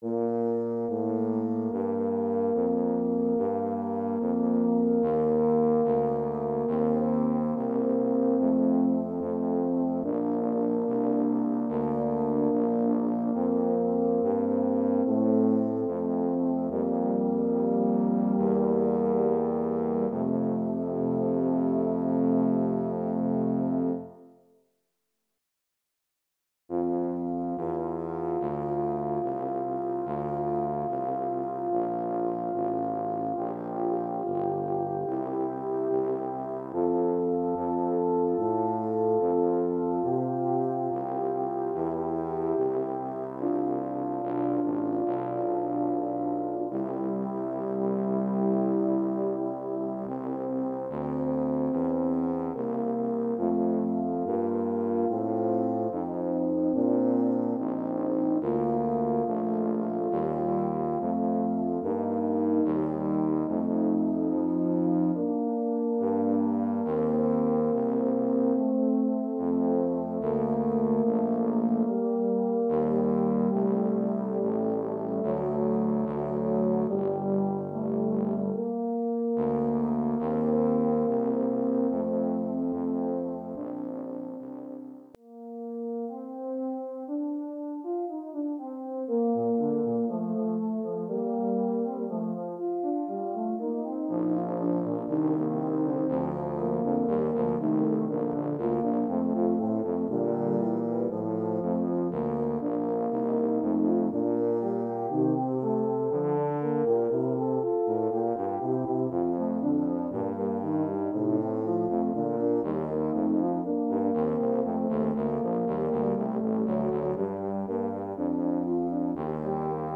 Voicing: Euphonium/Tuba Trio